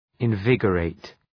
Προφορά
{ın’vıgə,reıt}